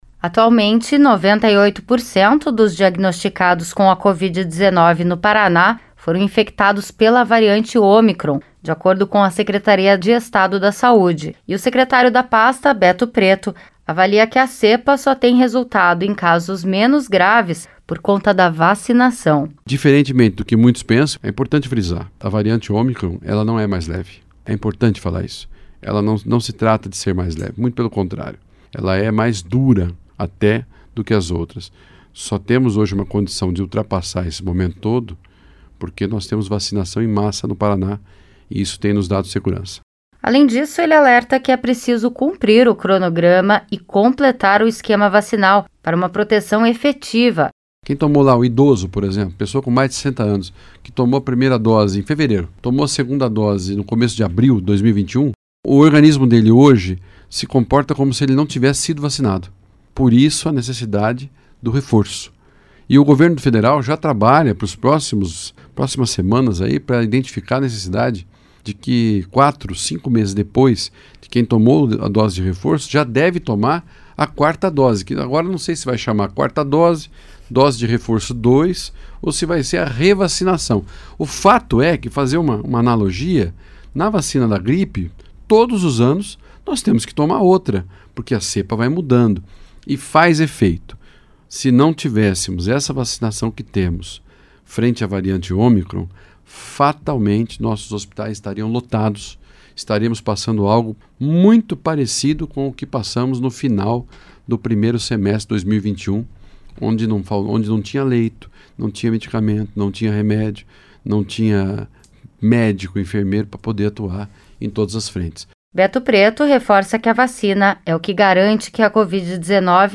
O secretário da Saúde do Paraná falou nesta quarta-feira sobre a variante Ômicron e o impacto dela na pandemia no Paraná.